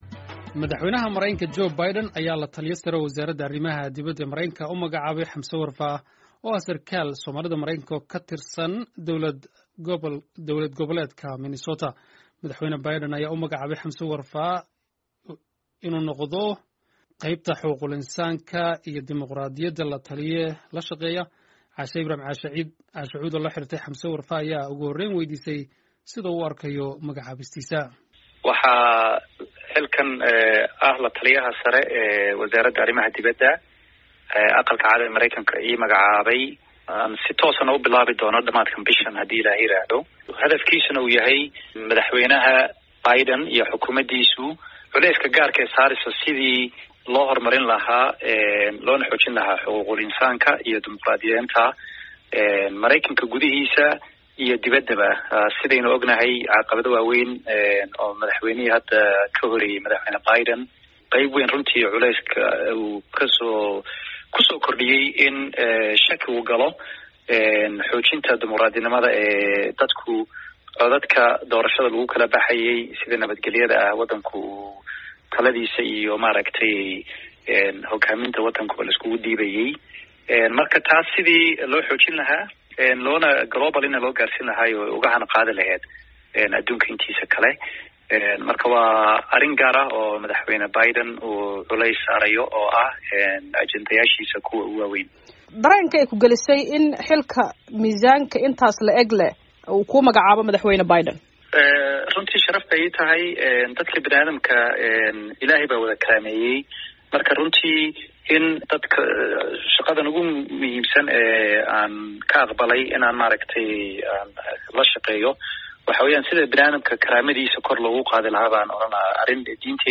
Wareysi: Madaxweyne Biden oo la-taliye sare u magacaabay Xamse Warfaa oo Soomaali ah
Halkan ka dhageyso wareysiga Hamse Warfaa